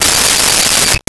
Cell Phone Ring 12
Cell Phone Ring 12.wav